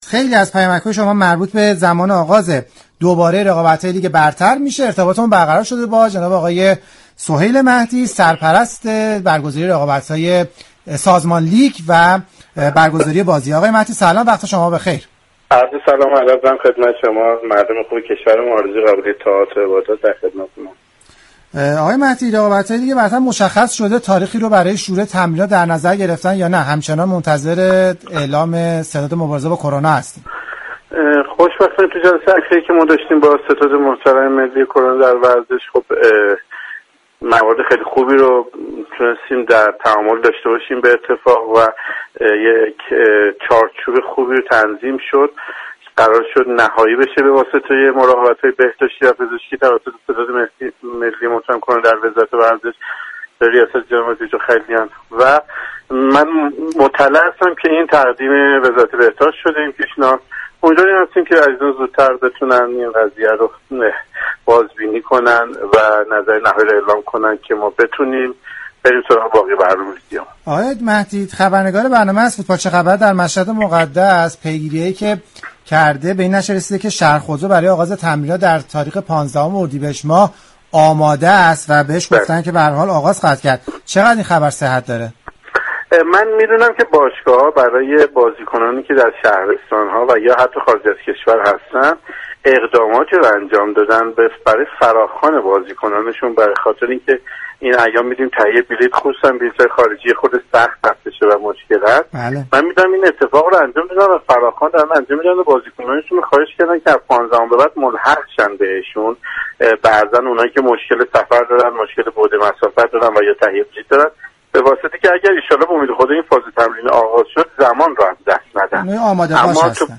برنامه زنده